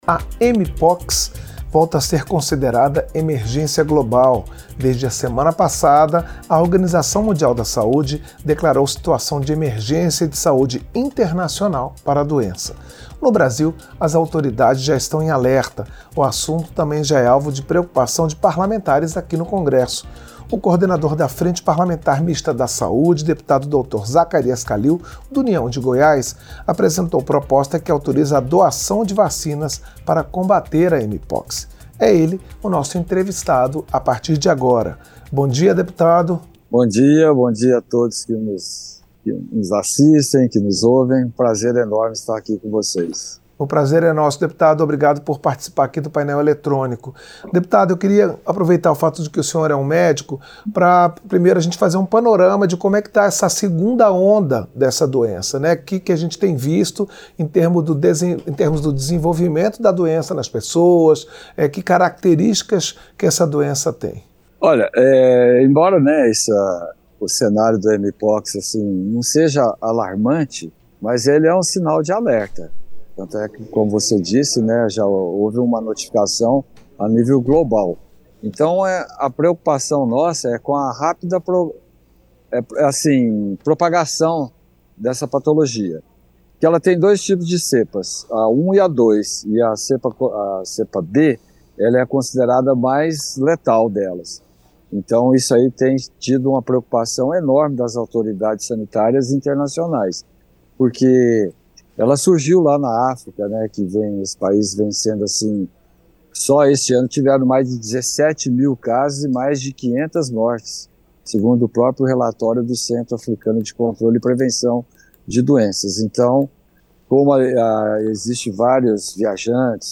Entrevista - Dep. Dr. Zacharias Calil (União-GO)